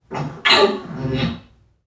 NPC_Creatures_Vocalisations_Robothead [34].wav